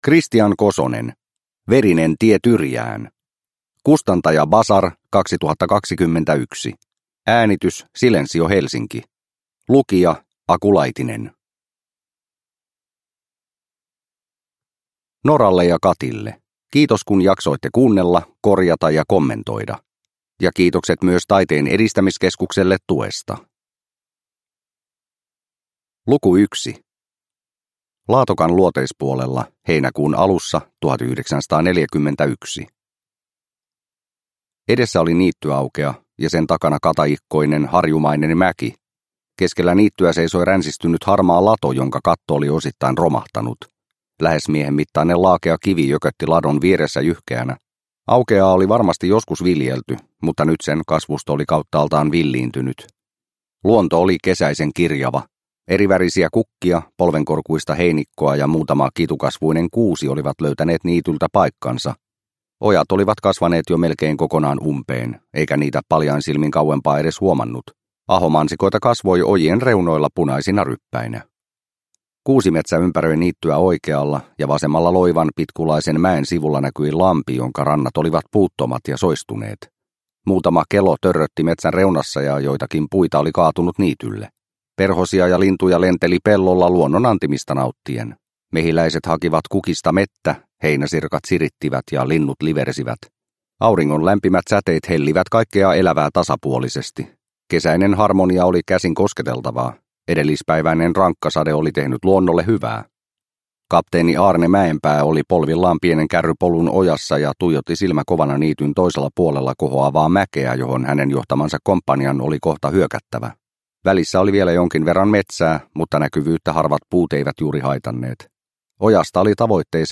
Verinen tie Tyrjään – Ljudbok – Laddas ner